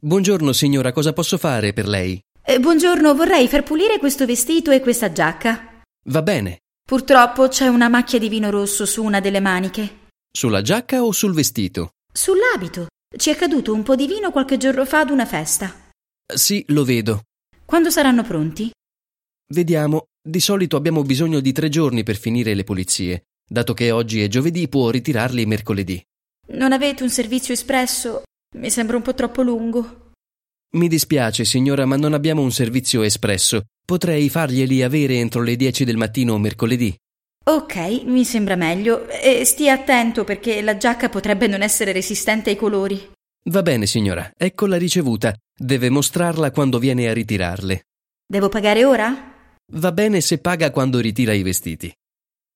HASZNOS OLASZ PÁRBESZÉD: Alla lavanderia - A mosodában
hasznos párbeszédek audióval